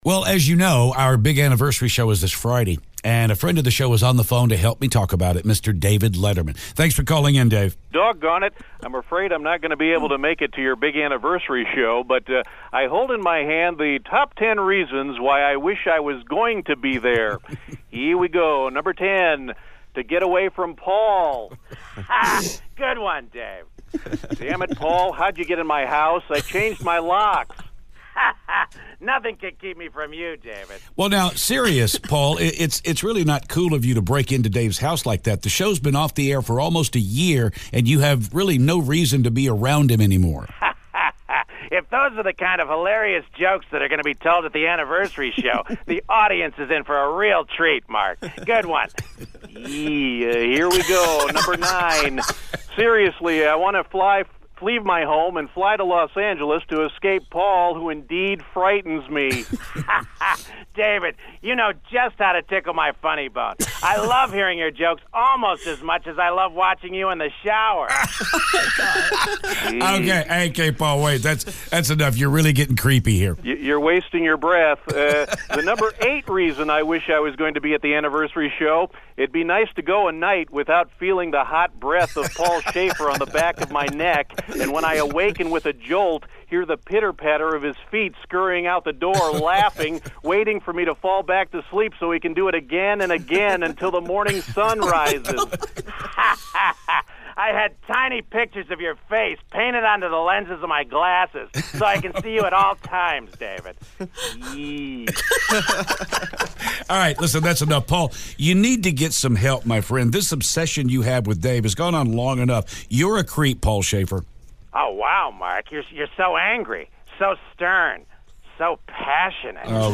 David Letterman calls with the top 10 reasons why he wishes he was going to be at the anniversary show.